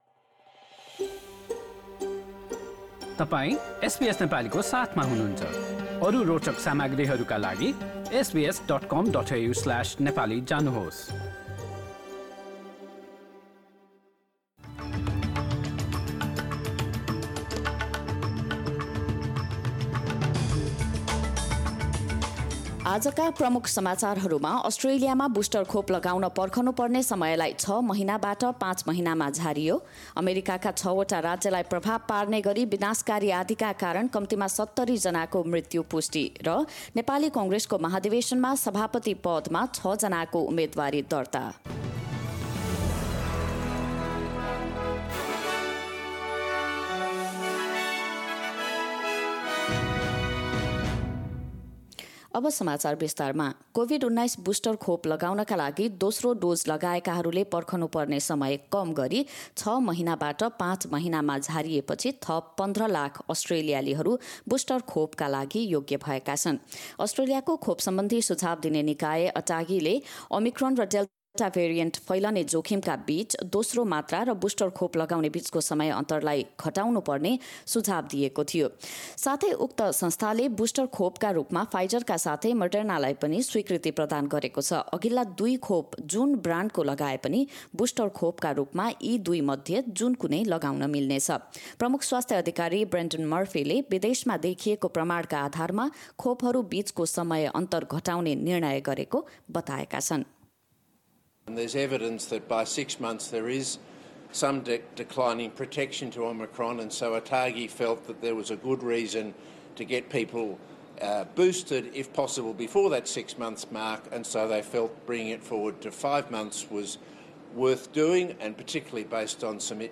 एसबीएस नेपाली अस्ट्रेलिया समाचार: आइतबार १२ डिसेम्बर २०२१